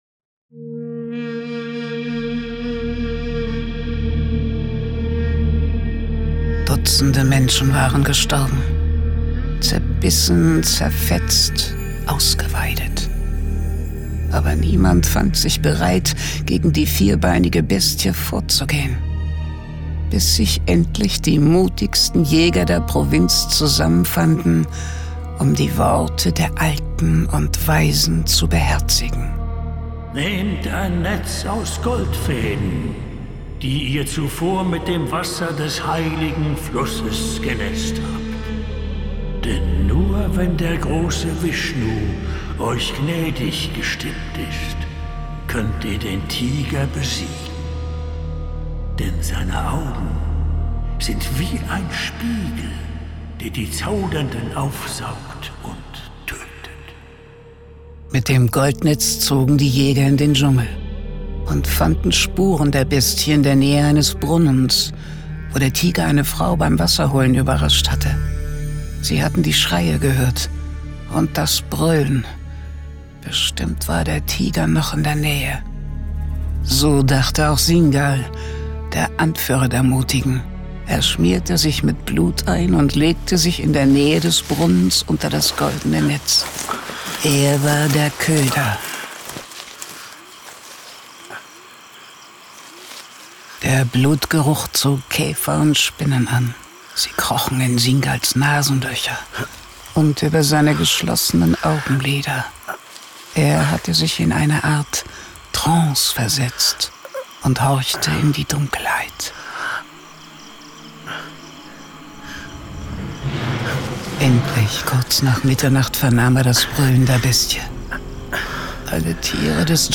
John Sinclair - Folge 171 Kalis tödlicher Spiegel. Hörspiel.